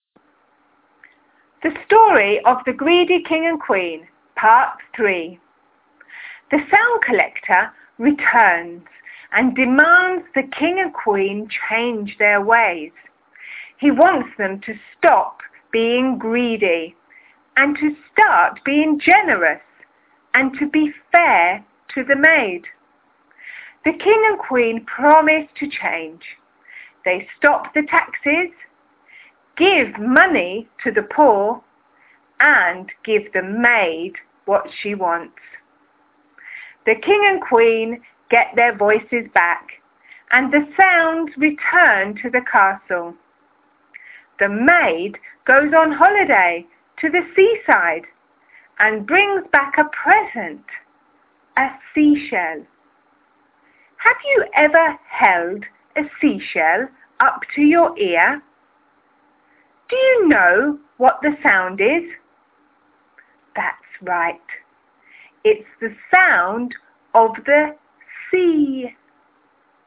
STORY PART 3